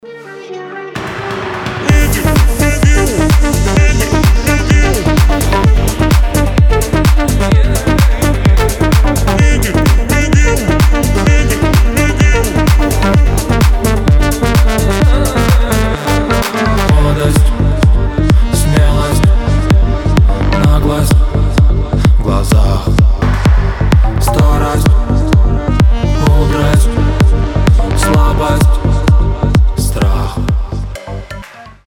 • Качество: 320, Stereo
мужской голос
house